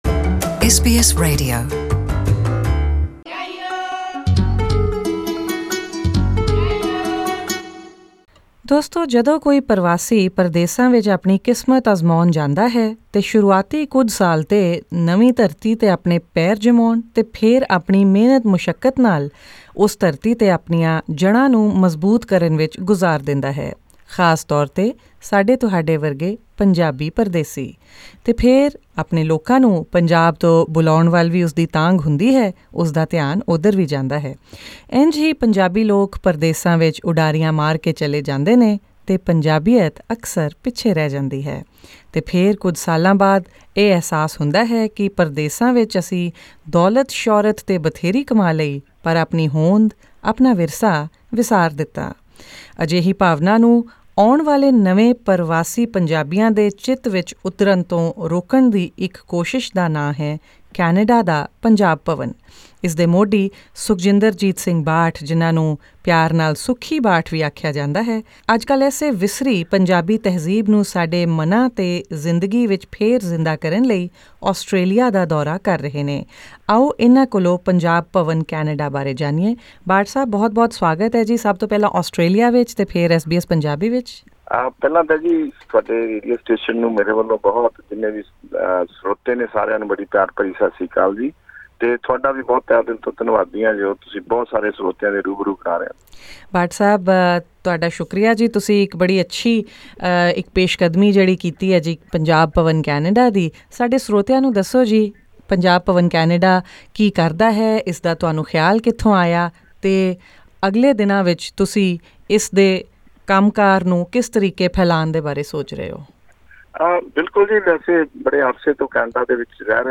detailed interview